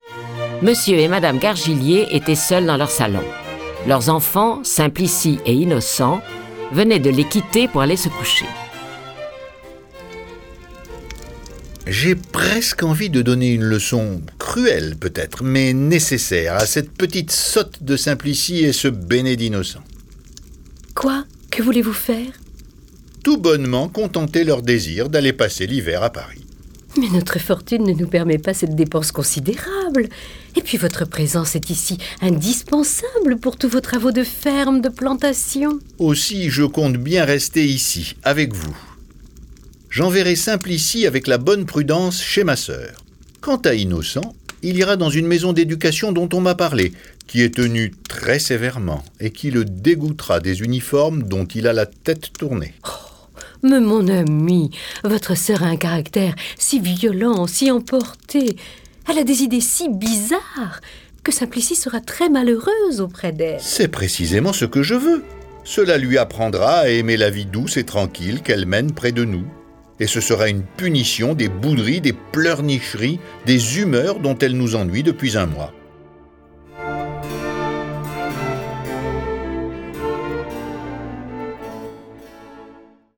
Diffusion distribution ebook et livre audio - Catalogue livres numériques
Cette version sonore des aventures de nos deux jeunes amis est animée par onze voix et accompagnée de près de trente morceaux de musique classique.
Le récit et les dialogues sont illustrés avec les musiques de Albinoni, Bach, Charpentier, Chopin, Corelli, Donizetti, Dvorak, Grieg, Haydn, Liszt, Marcello, Mozart, Rossini, Schumann, Strauss, Tchaïkovski, Telemann, Vivaldi, Wagner.